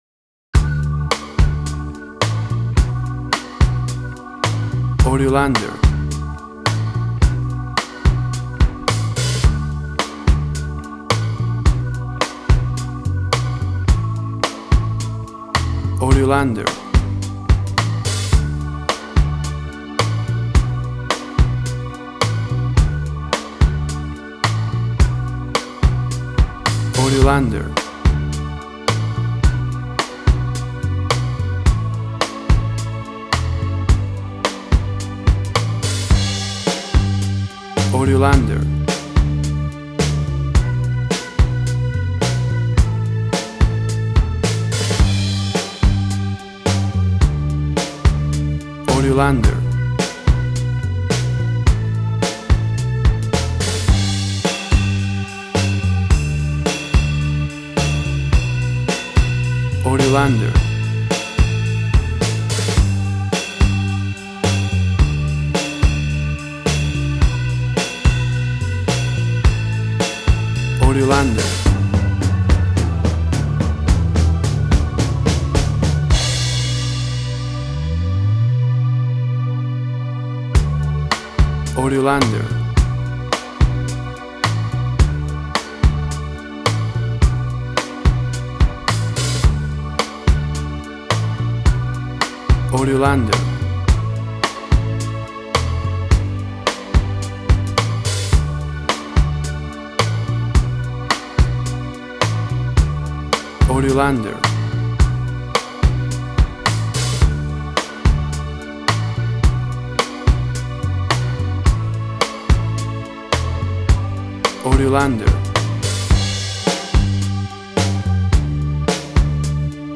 WAV Sample Rate: 16-Bit stereo, 44.1 kHz
Tempo (BPM): 108